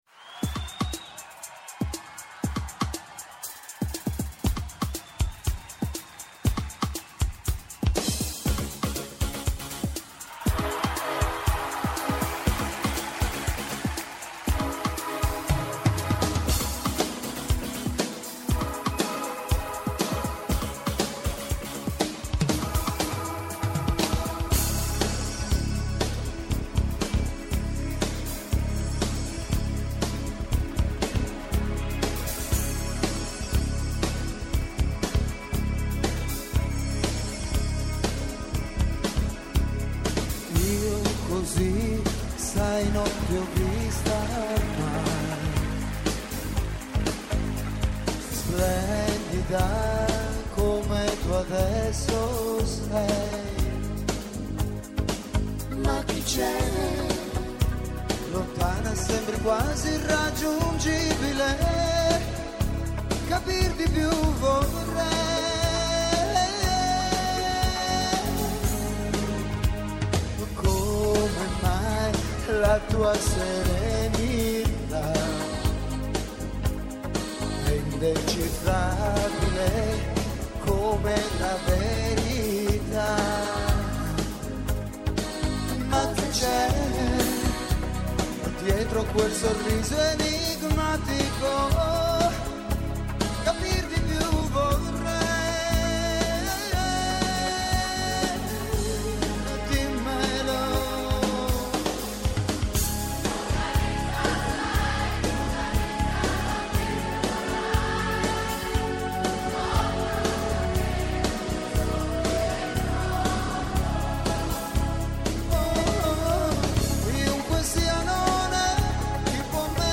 Καλεσμένοι σήμερα